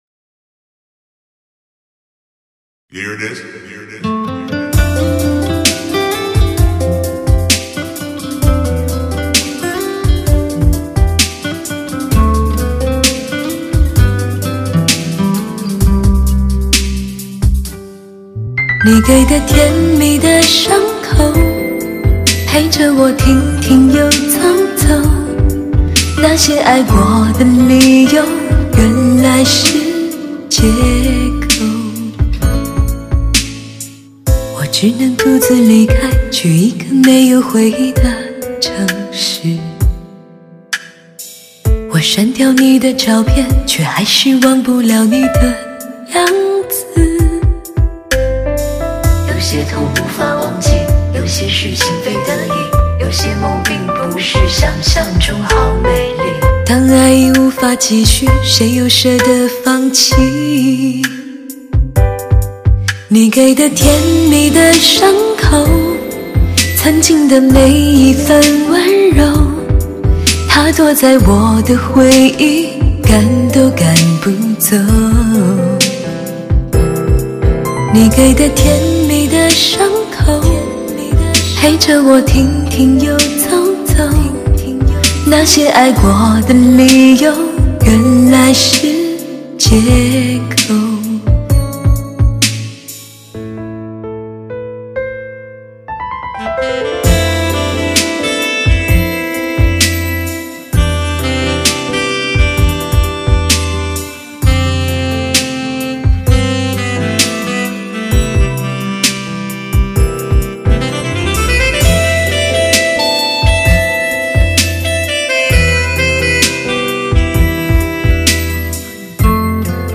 无可挑剔的完美录音 声色俱佳的靓绝旋律！